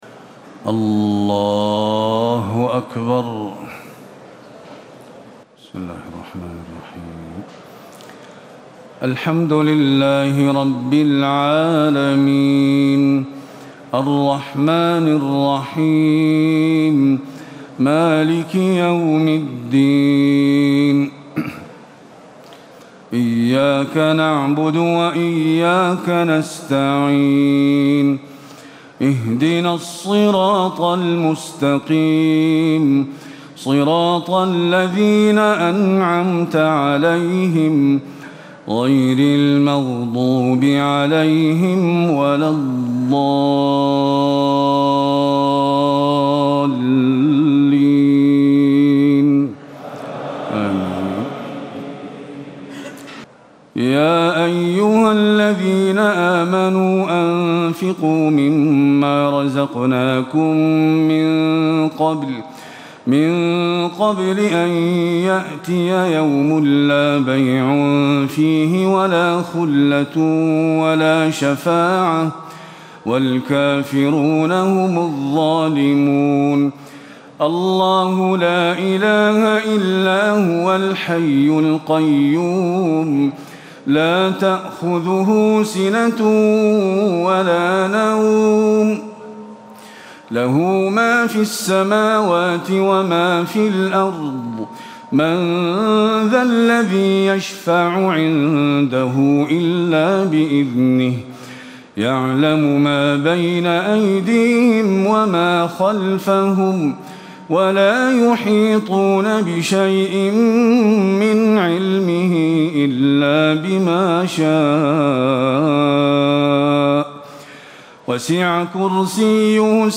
تهجد ليلة 23 رمضان 1437هـ من سورتي البقرة (254-286) و آل عمران (1-32) Tahajjud 23 st night Ramadan 1437H from Surah Al-Baqara and Aal-i-Imraan > تراويح الحرم النبوي عام 1437 🕌 > التراويح - تلاوات الحرمين